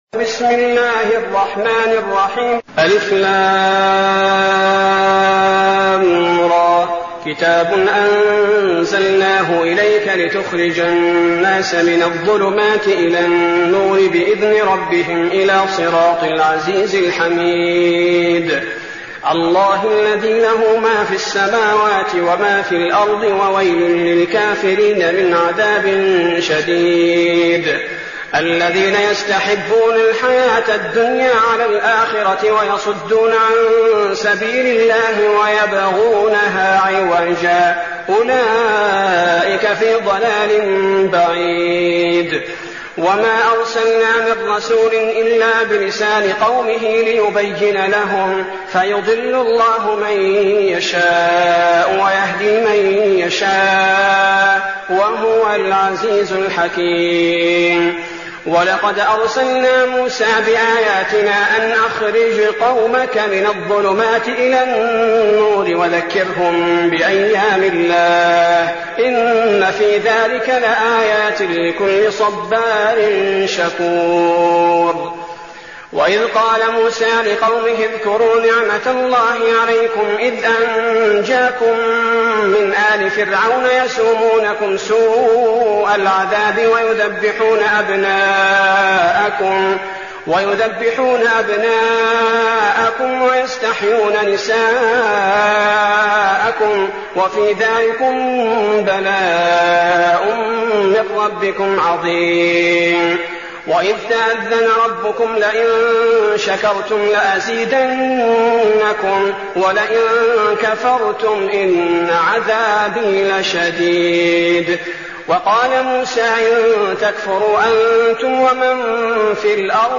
المكان: المسجد النبوي الشيخ: فضيلة الشيخ عبدالباري الثبيتي فضيلة الشيخ عبدالباري الثبيتي إبراهيم